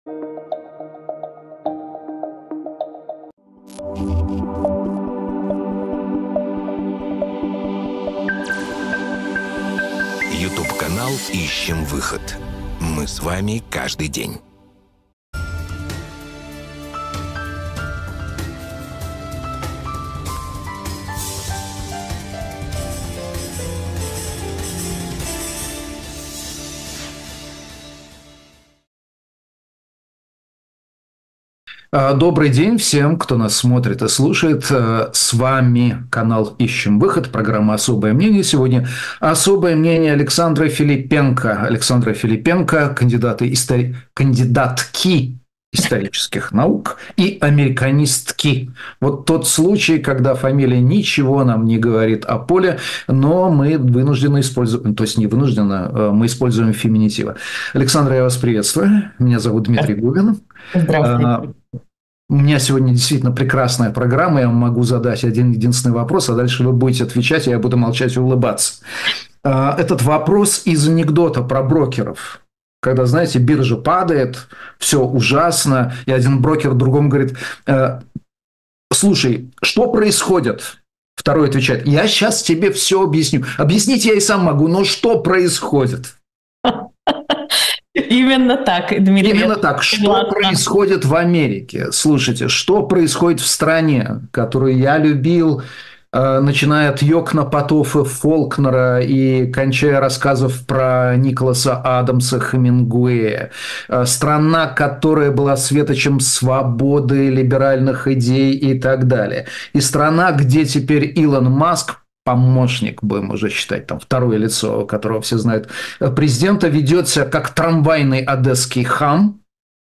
Эфир ведёт Дмитрий Губин